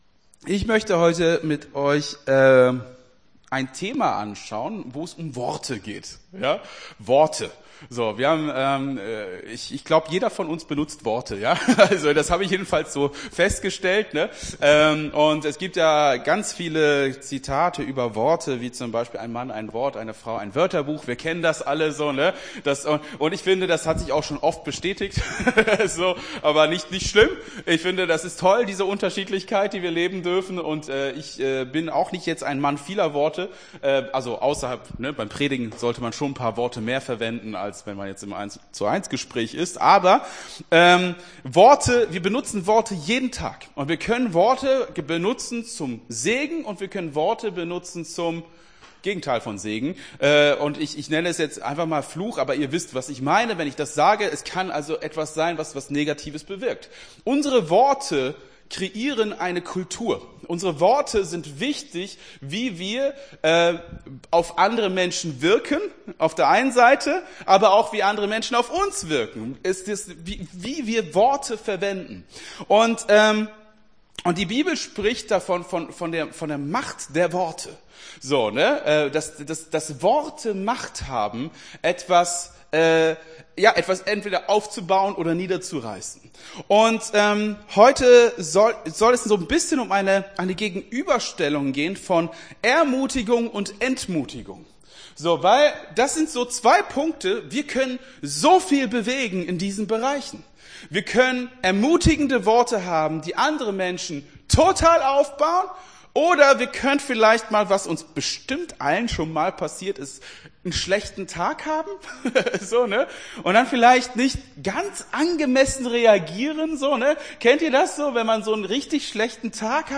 Gottesdienst 16.04.23 - FCG Hagen